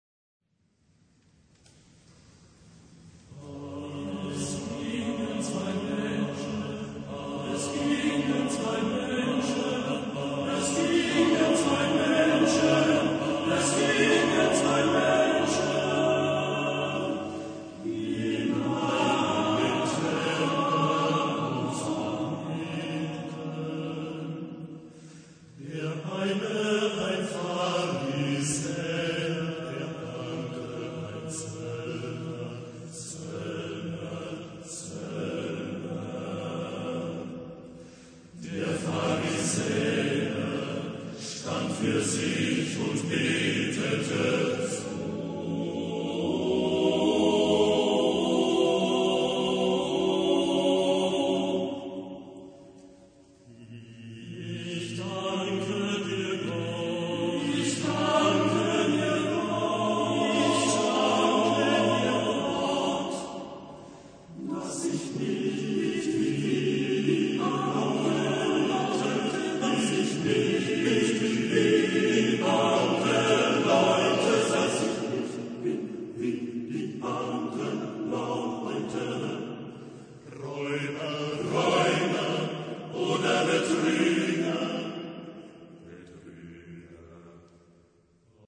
Epoque: 20th century
Type of Choir: TTBB  (4 men voices )